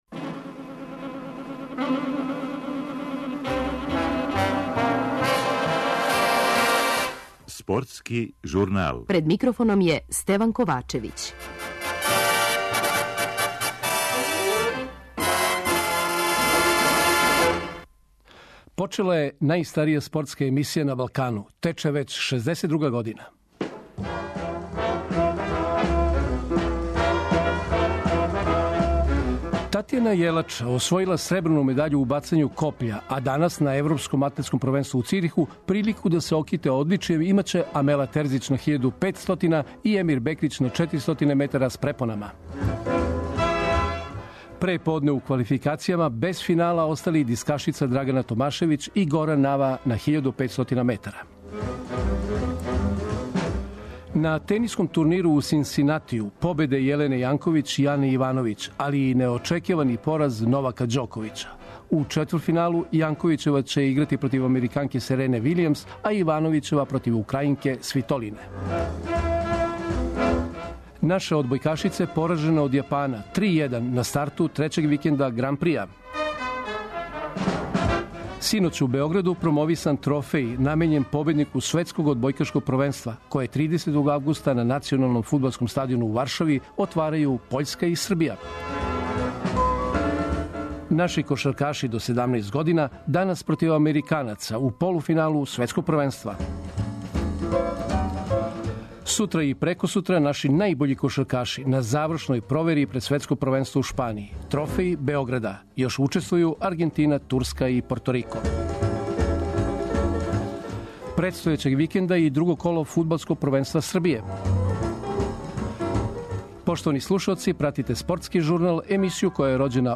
преузми : 13.58 MB Спортски журнал Autor: Спортска редакција Радио Београда 1 Слушајте данас оно о чему ћете читати у сутрашњим новинама!